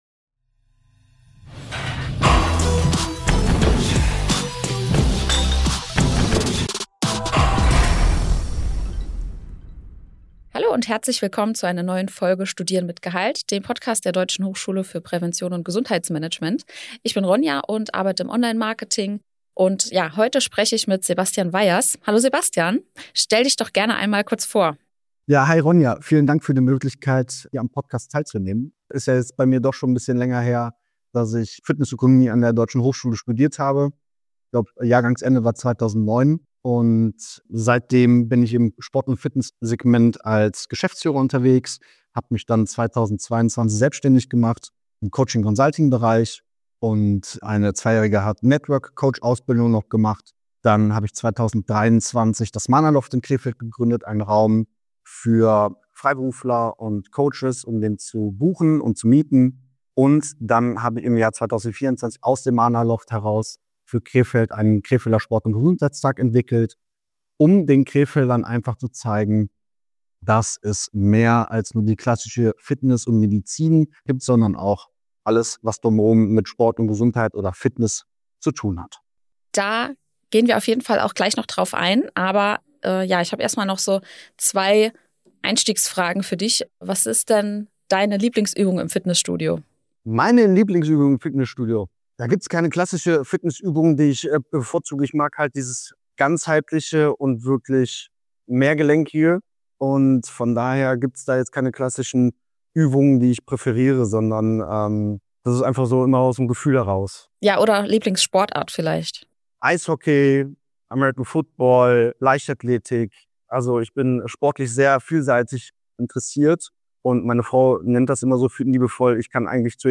Ein spannendes Gespräch über Fitnessökonomie, Unternehmertum und die Verwirklichung von Visionen!